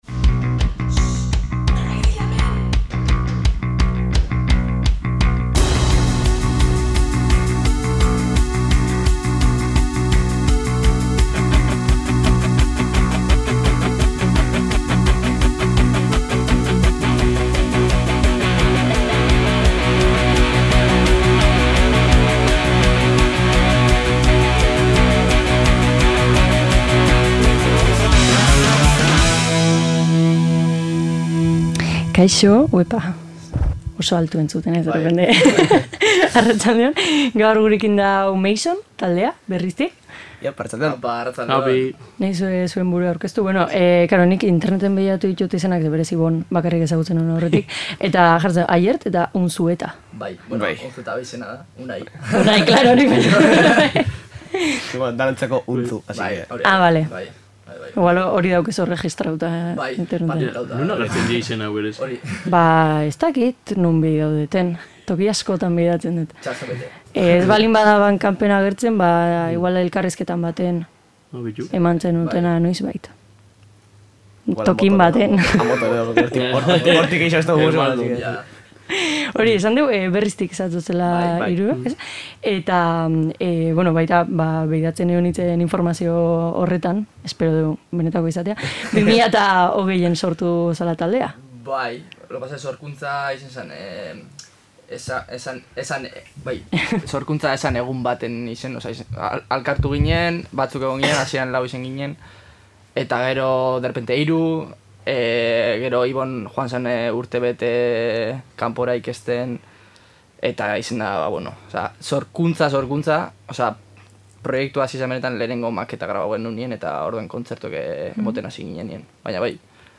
Meison osatzen duten hiru lagunekin hitz egiten egon gara, haien proiektuarekin lotutako hainbat gaien inguruan.